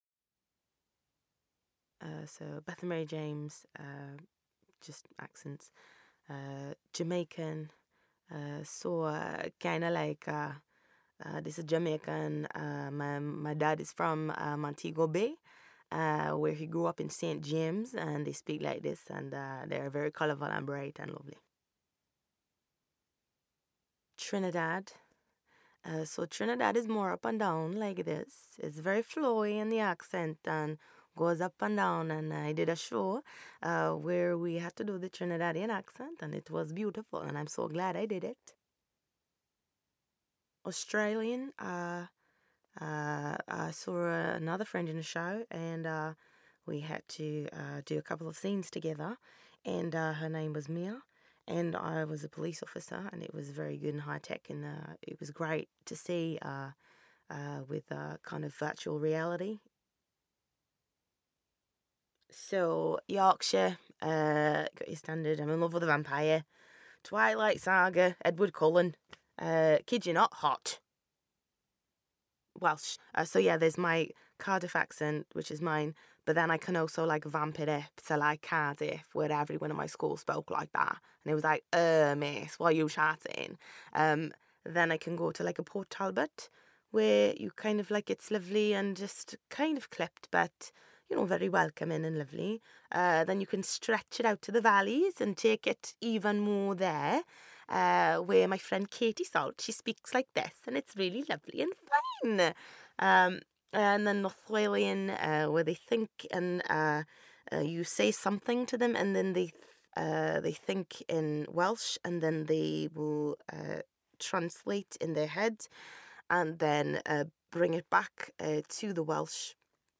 Accents- Jamaican, Trinidad, Australian, Yorkshire, Welsh, Cardiff , Port Talbot, The Valleys, North Walian, African, French, Liverpool